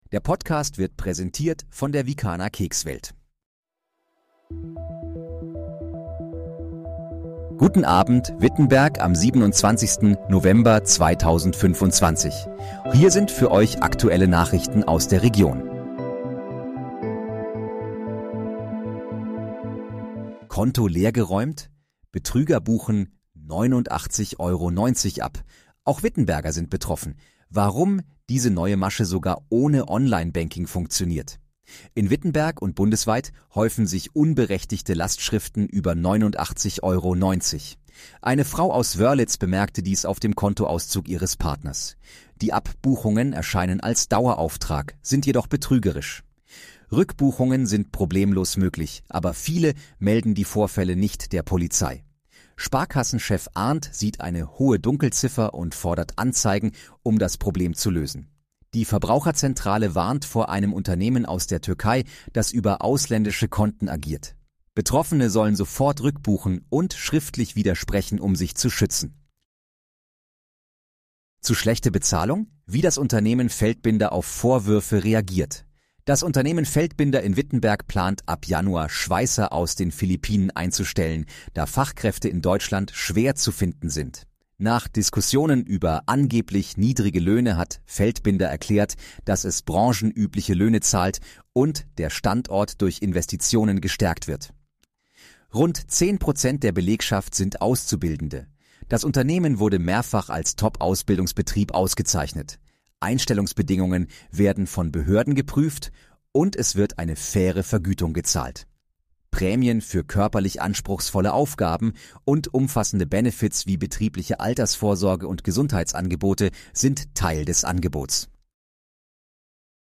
Guten Abend, Wittenberg: Aktuelle Nachrichten vom 27.11.2025, erstellt mit KI-Unterstützung
Nachrichten